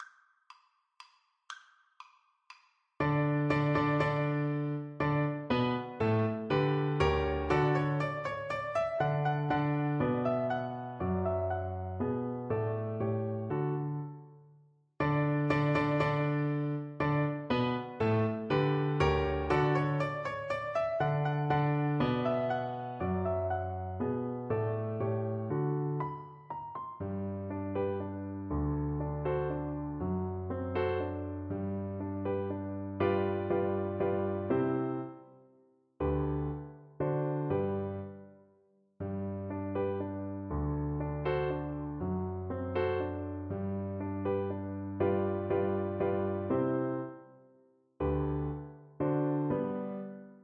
La Celestina Violin version
Free Sheet music for Violin
Violin
D major (Sounding Pitch) (View more D major Music for Violin )
3/4 (View more 3/4 Music)
Allegro (View more music marked Allegro)
C5-F#6
Classical (View more Classical Violin Music)